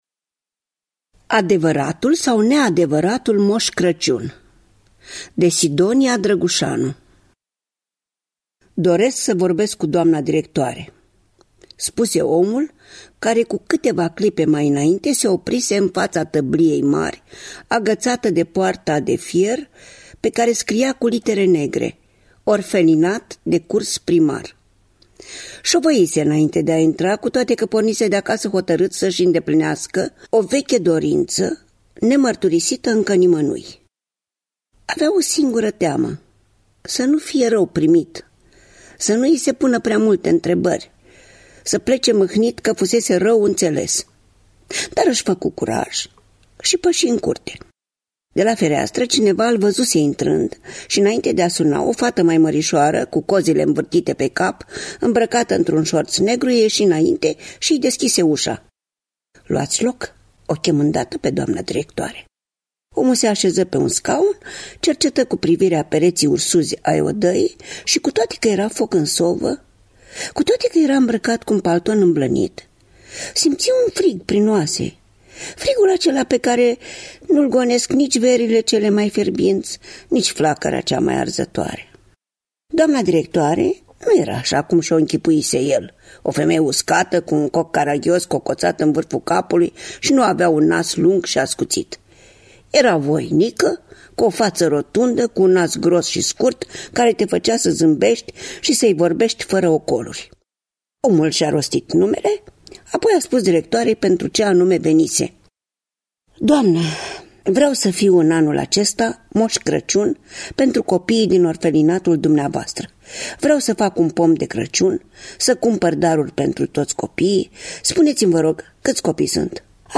Citește: Rodica Mandache.